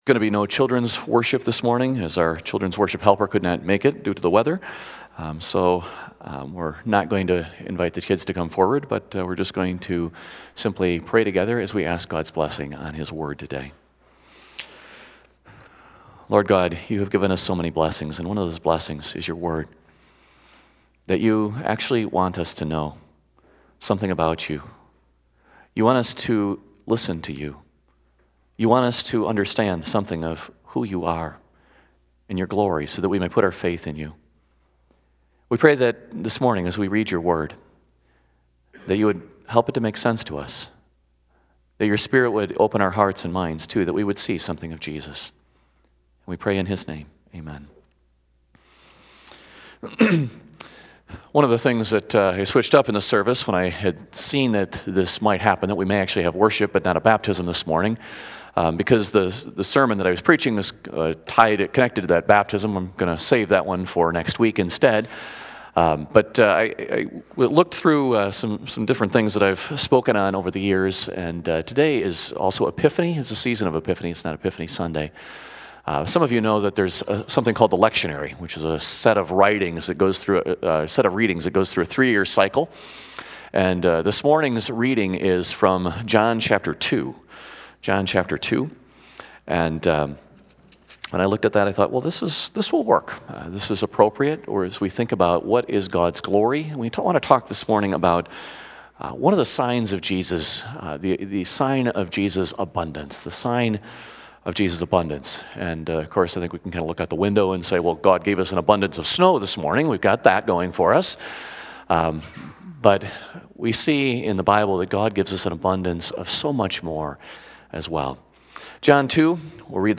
2019 Sermons - Fairlawn Christian Reformed Church